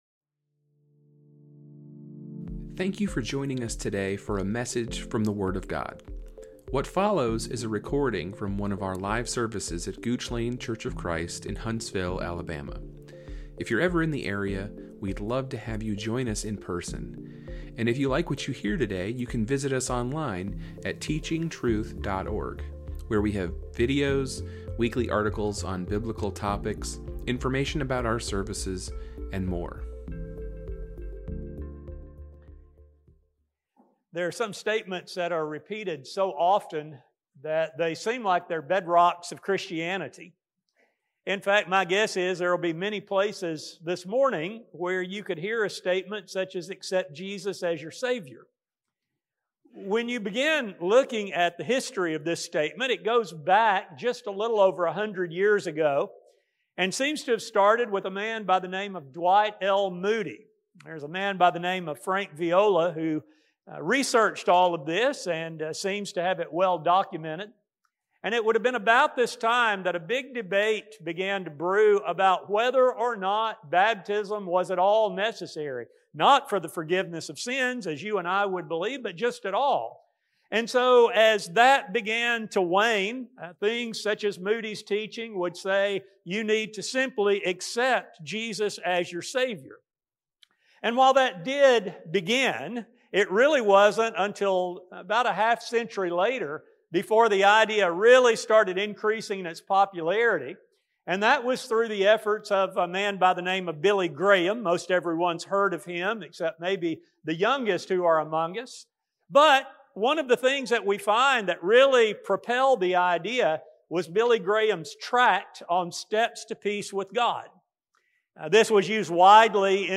Gooch Lane Church of Christ Podcast
This study will focus on the accuracy of this statement and how one should view Jesus and His offer of salvation. A sermon